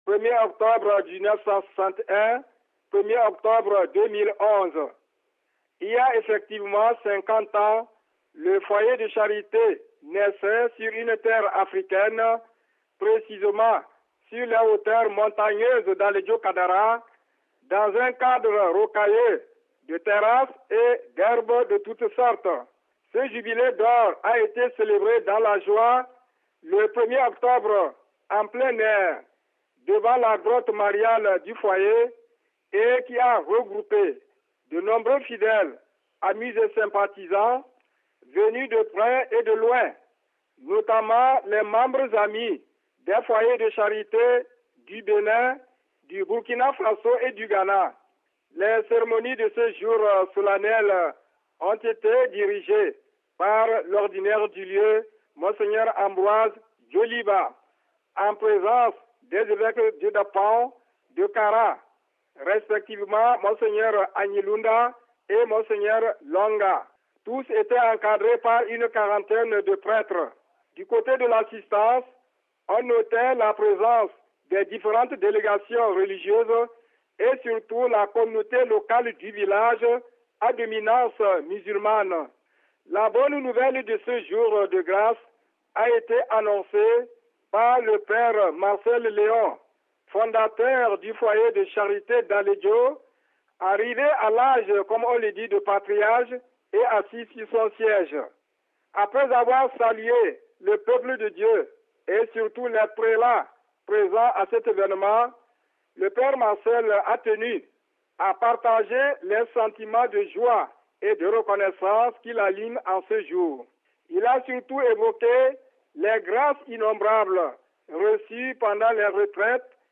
Le foyer de Charité d'Alédjo, un centre de retraites spirituelles inspirée par la laïque française, Marthe Robin, a célébré tout récemment les 50 ans de sa présence dans le pays, donc en Afrique. Des explications avec notre correspondant local